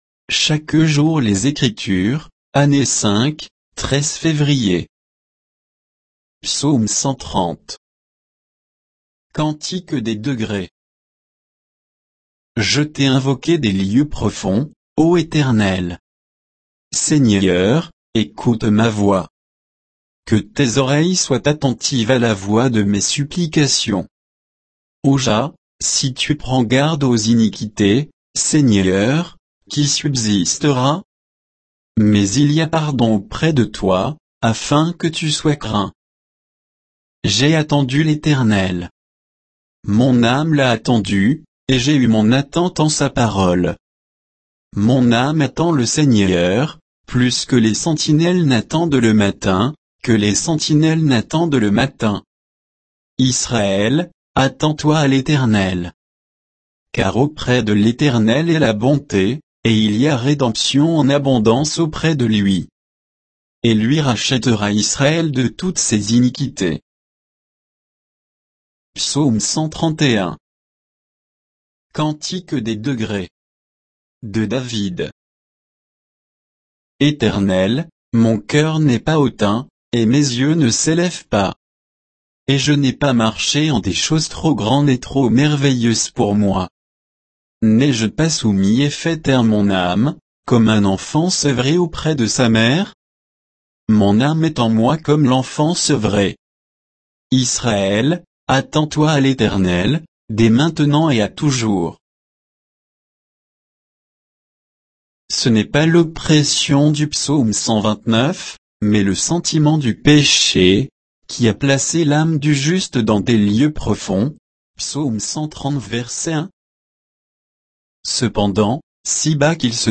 Méditation quoditienne de Chaque jour les Écritures sur Psaumes 130 et 131